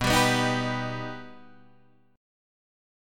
B+ Chord
Listen to B+ strummed